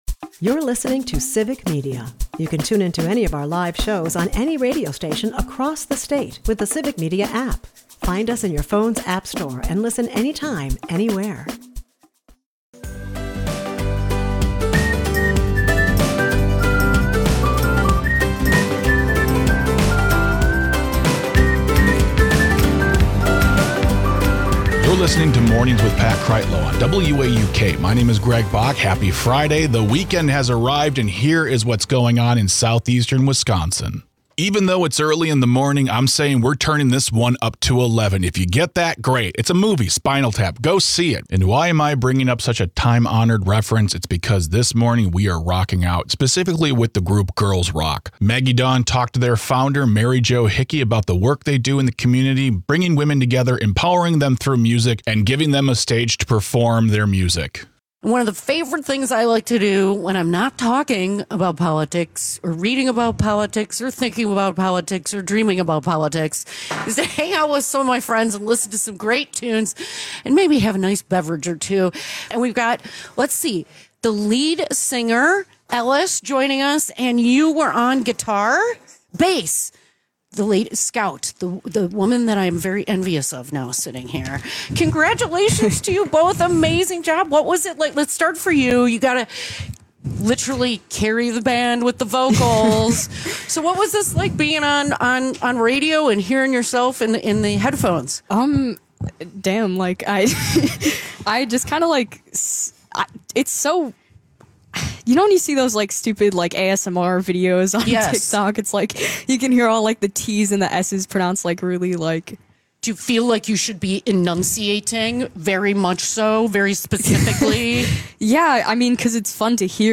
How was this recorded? WAUK Morning Report is a part of the Civic Media radio network and air four times a morning.